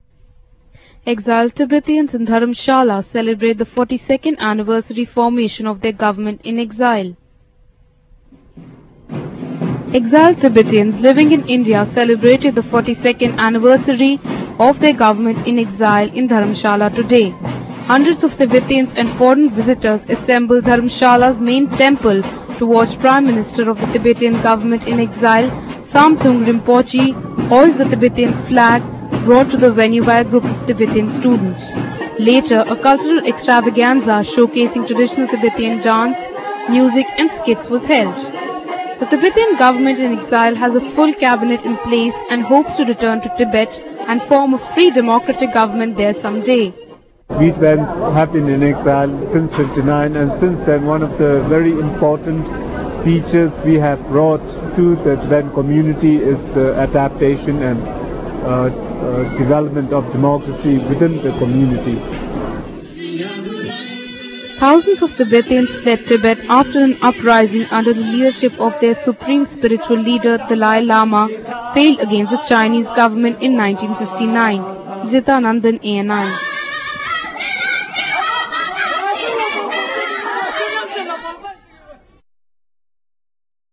Exiled Tibetans at Dharamsala celebrate the 42nd anniversary of the formation of their government in exile.